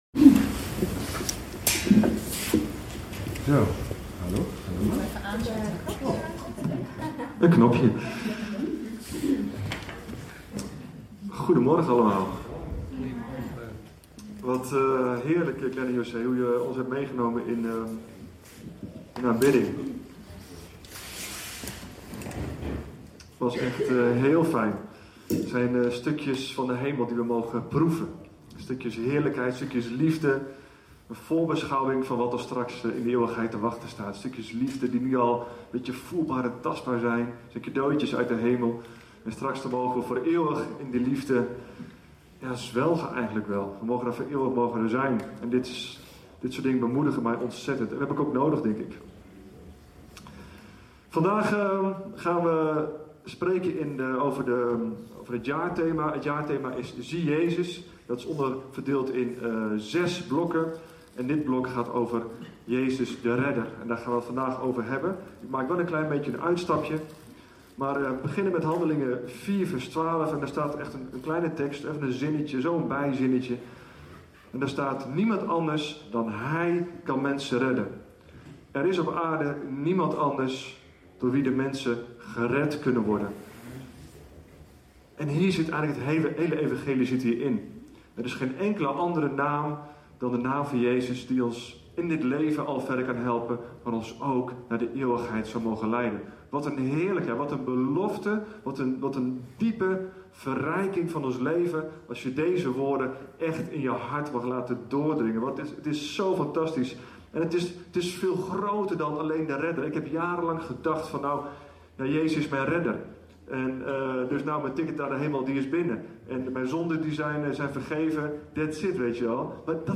In deze podcast staan alle opgenomen toespraken van Leef! Zutphen vanaf 3 februari 2008 t/m nu.